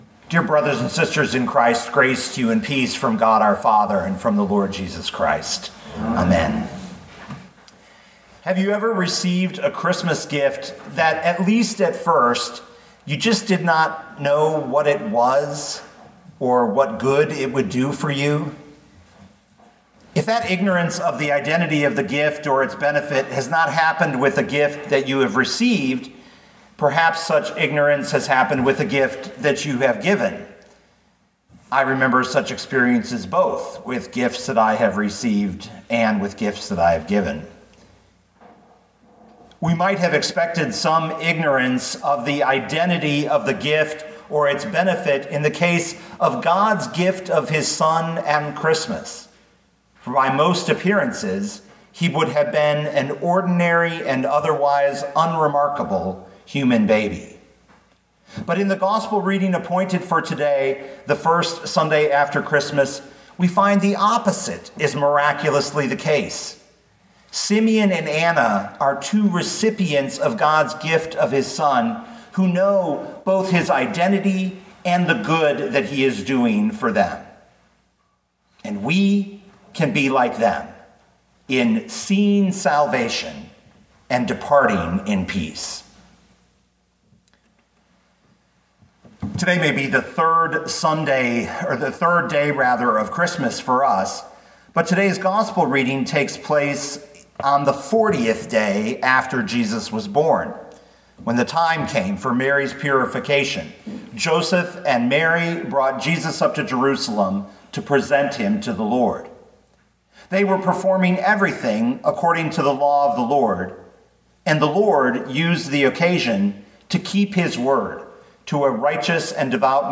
2020 Luke 2:22-40 Listen to the sermon with the player below, or, download the audio.